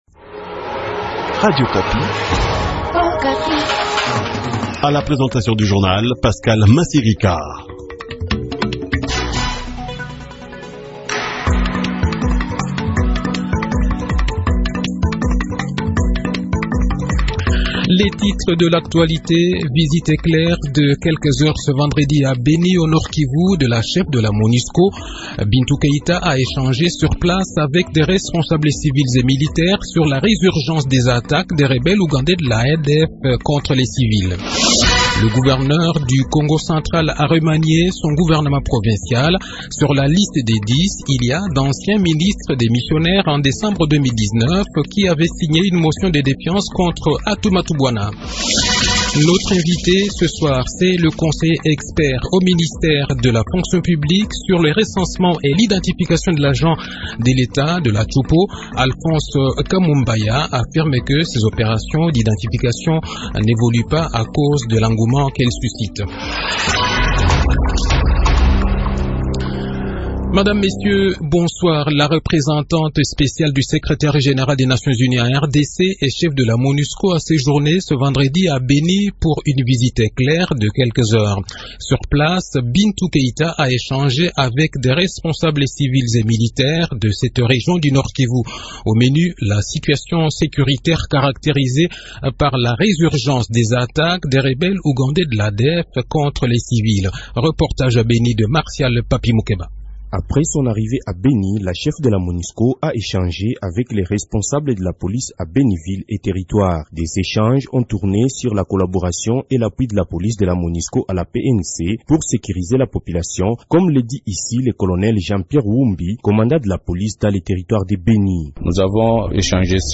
Le journal-Français-Soir
Le journal de 18 h, 12 Mars 2021